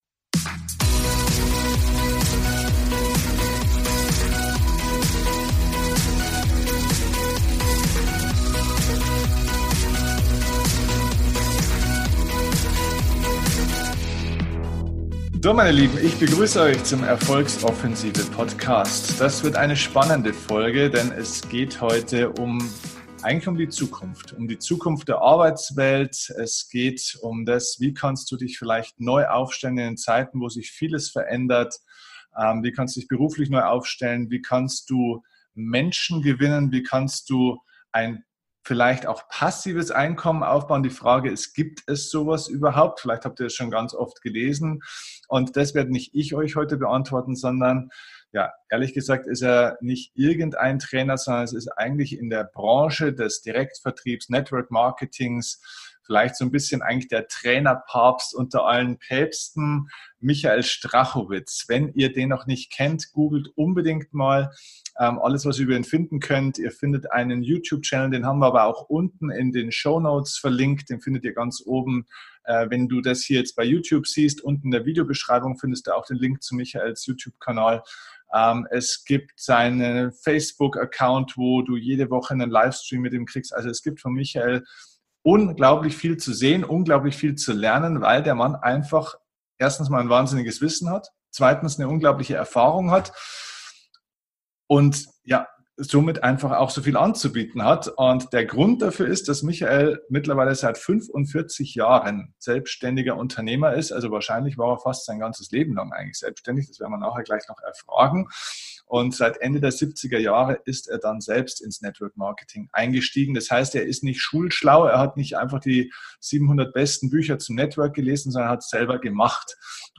# 260 Ist Network Marketing Betrug oder die Zukunft - Interview